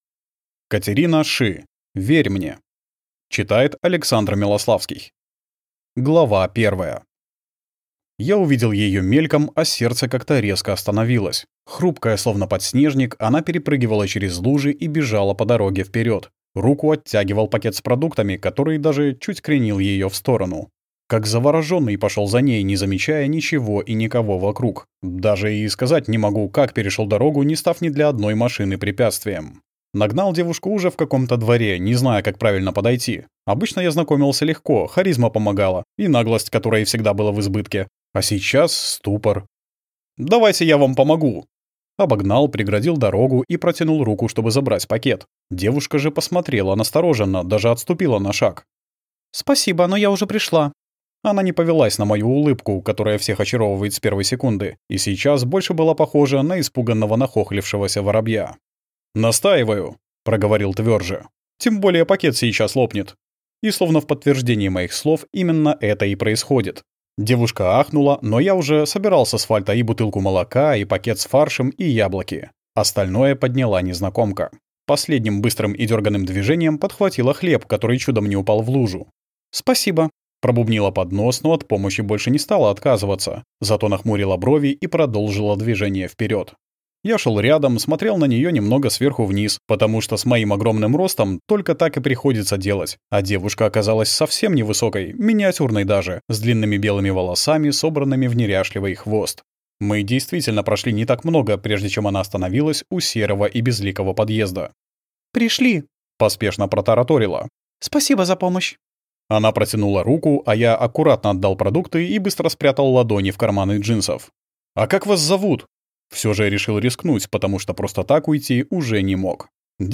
Аудиокнига Верь мне | Библиотека аудиокниг